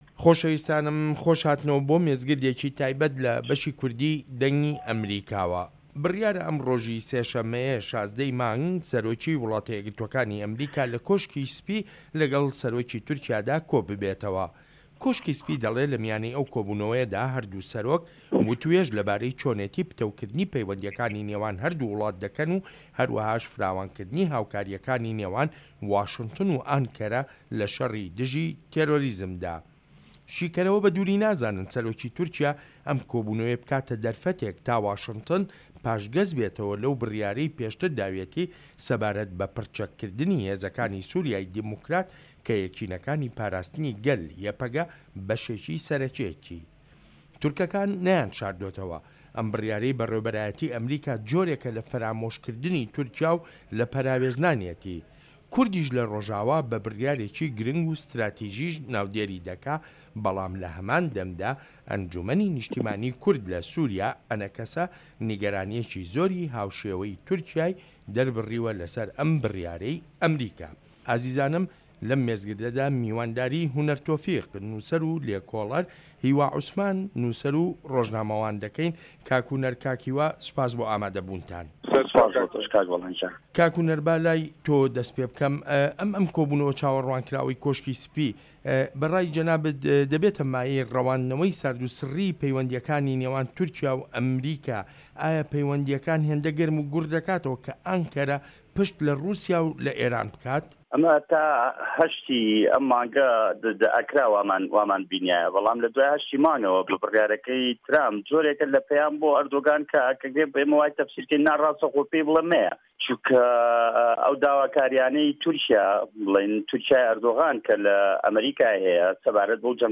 مێزگرد: ئەردۆغان لە کۆشکی سپی